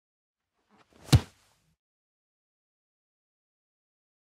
Tiếng Sút Bóng, phát bóng lên… khi đá bóng
Thể loại: Tiếng động
Description: Tiếng Sút Bóng, phát bóng lên, đá bóng, tiếng xút bóng, tiếng đập bóng, tiếng chạm bóng, phát bóng lên cao.
tieng-sut-bong-phat-bong-len-khi-da-bong-www_tiengdong_com.mp3